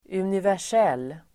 Ladda ner uttalet
universell adjektiv, universal Uttal: [univär_s'el:] Böjningar: universellt, universella Synonymer: allmän, allomfattande Definition: universal- Exempel: ett universellt problem (a universal problem)